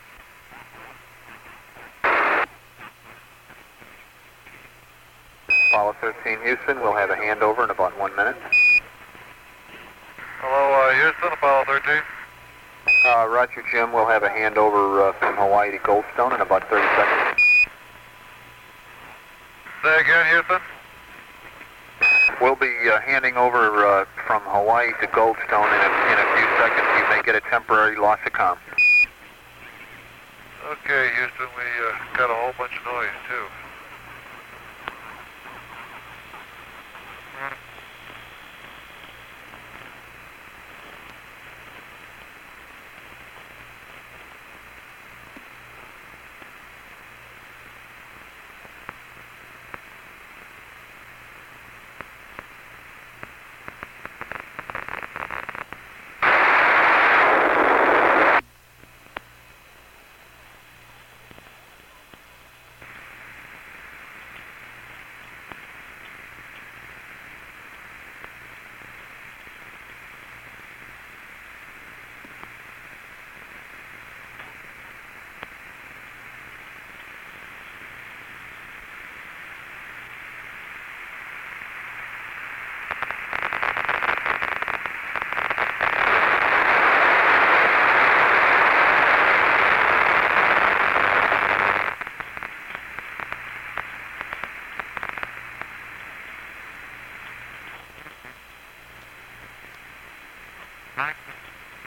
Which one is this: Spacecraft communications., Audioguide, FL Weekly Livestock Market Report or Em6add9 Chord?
Spacecraft communications.